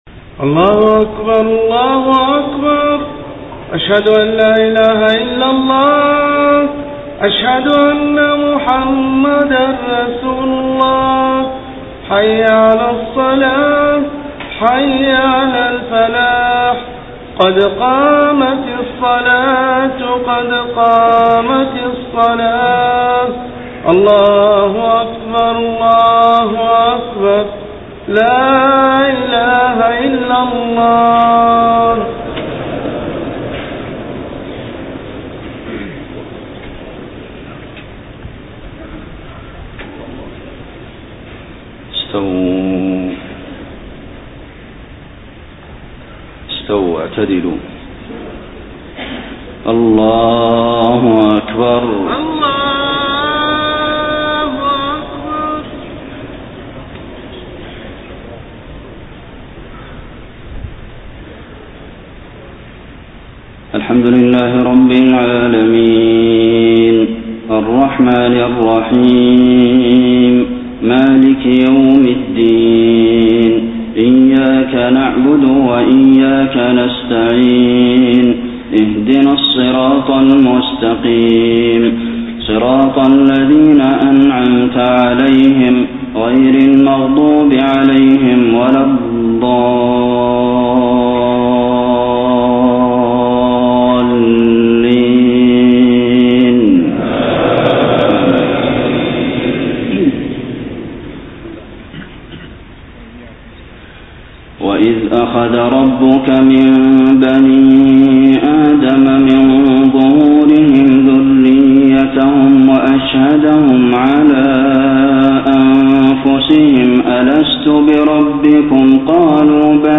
صلاة العشاء 15 ربيع الأول 1431هـ من سورة الأعراف 172-186 > 1431 🕌 > الفروض - تلاوات الحرمين